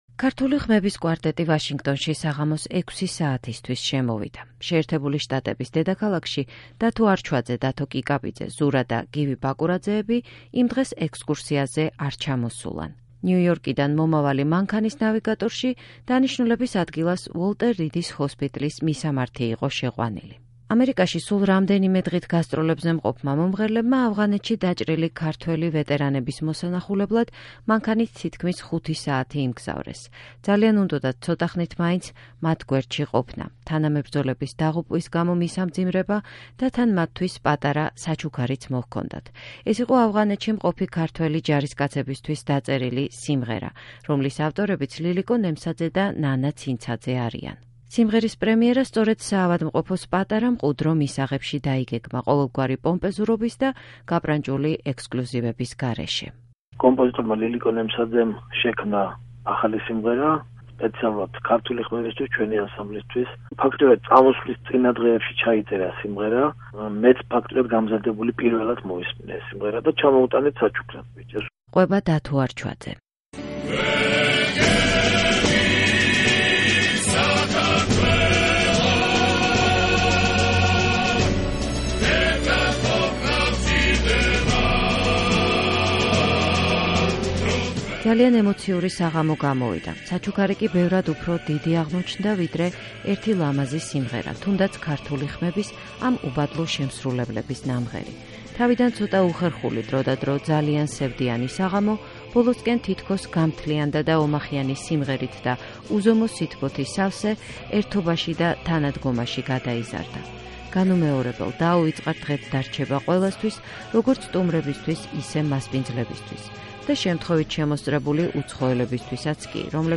დაუვიწყარი შეხვედრა და იმპროვიზირებული კონცერტი ვოლტერ რიდის ჰოსპიტლის მისაღებში